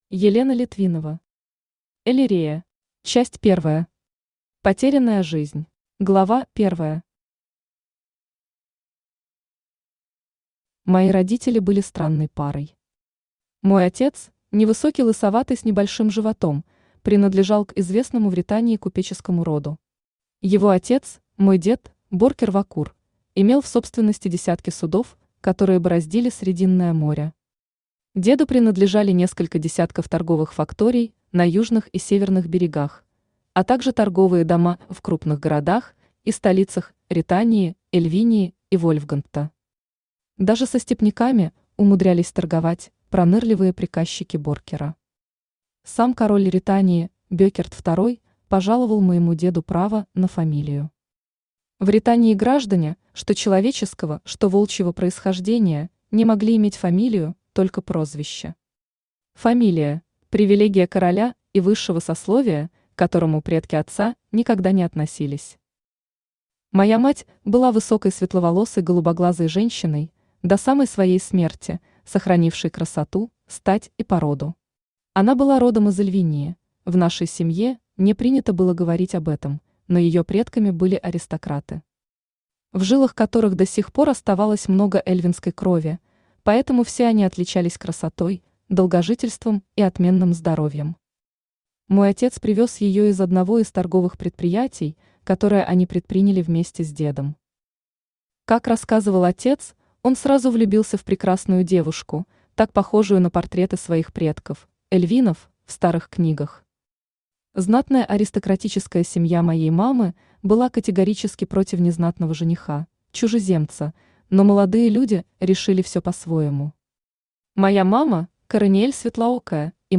Аудиокнига Эллирея | Библиотека аудиокниг
Aудиокнига Эллирея Автор Елена Литвинова Читает аудиокнигу Авточтец ЛитРес.